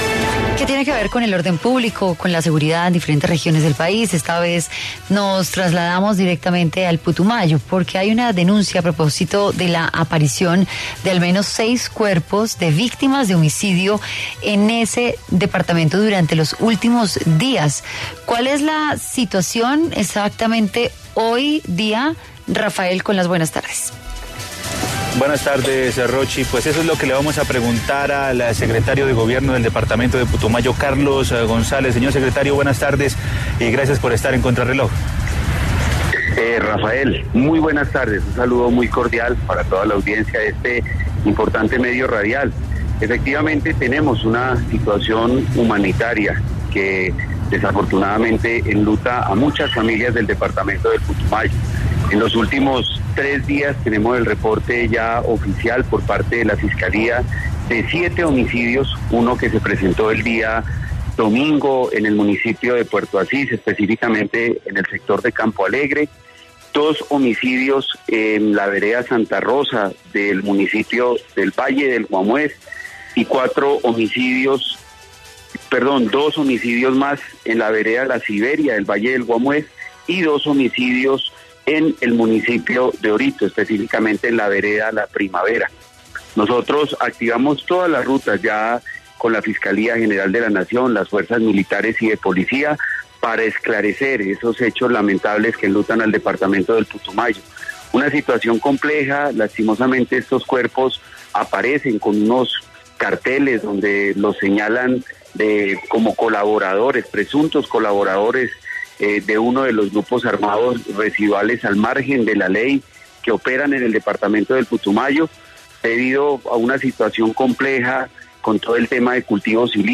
En entrevista con Contrarreloj el secretario de gobierno del departamento de Putumayo, Carlos González, respondió a la denuncia de la Red de Derechos Humanos de ese territorio, sobre al menos seis homicidios en los últimos 3 días.